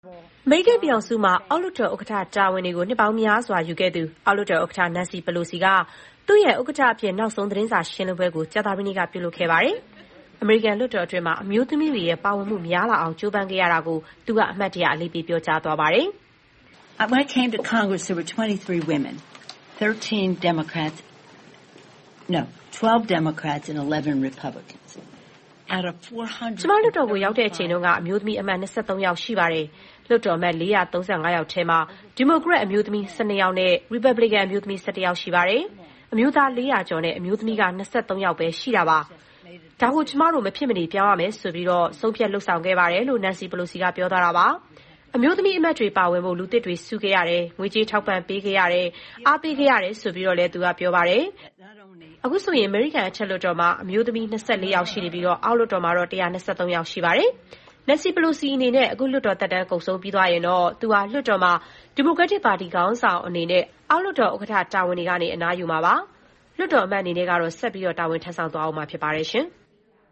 Nancy Pelosi အောက်လွှတ်တော်ဥက္ကဋ္ဌအဖြစ် နောက်ဆုံးသတင်းစာရှင်းပွဲ .mp3